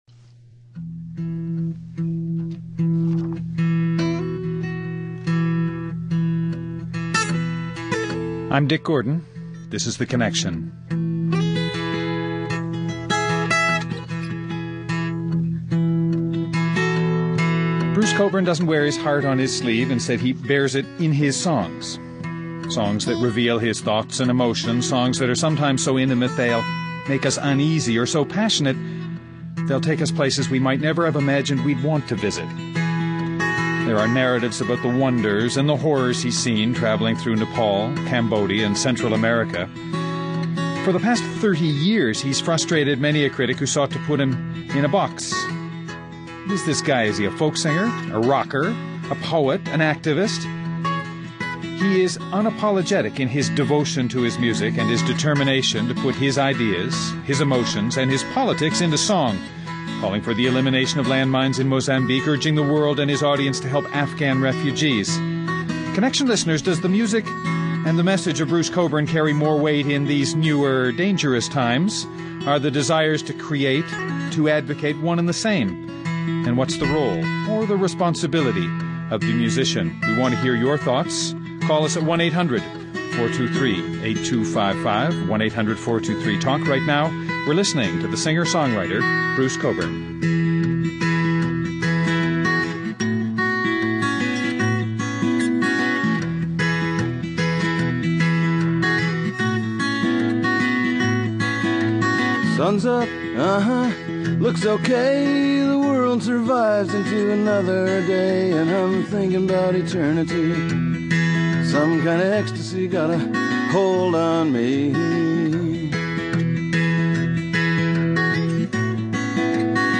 Guests: Bruce Cockburn, singer, songwriter, activist.